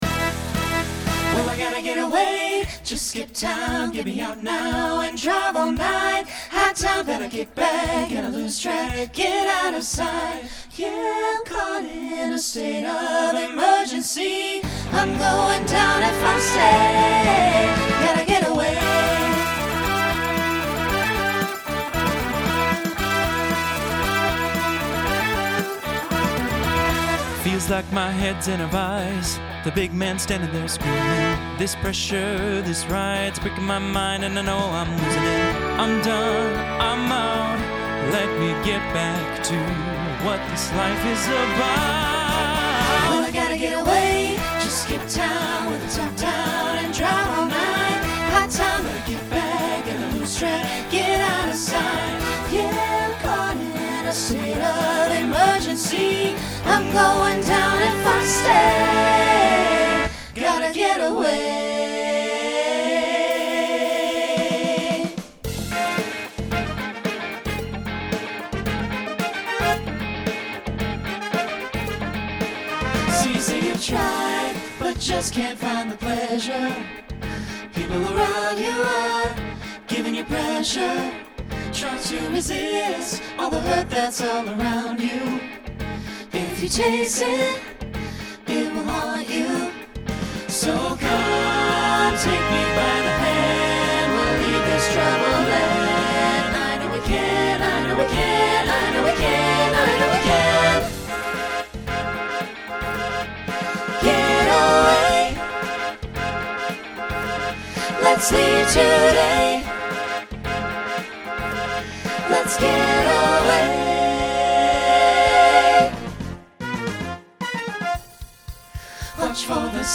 Genre Country , Rock
Voicing SATB